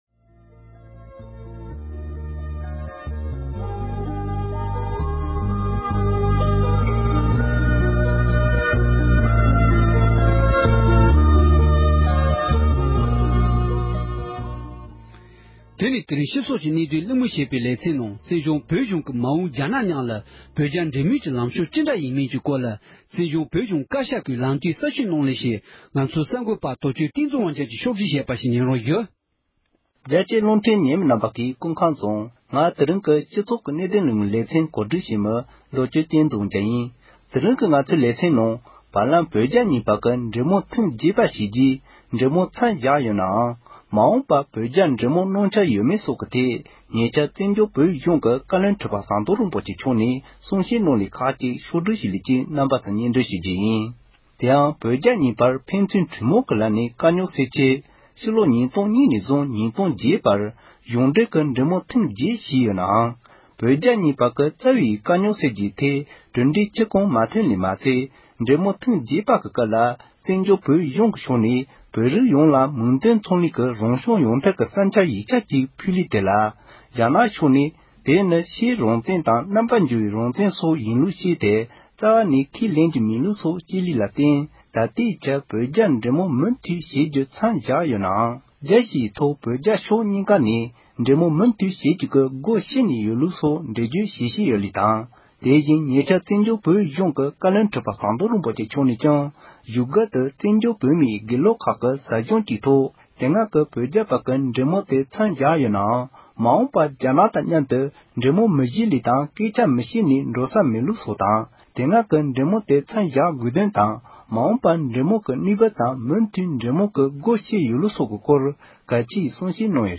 བཙན་བྱོལ་བོད་གཞུང་བཀའ་བློན་ཁྲི་པ་མཆོག་གིས་མ་འོངས་བོད་རྒྱ་འབྲེལ་མོལ་གནང་འཆར་ཡོད་མེད་ཐད་གསུང་བཤད་གནང་བ་ཁག